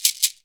07_Hats_15_SP.wav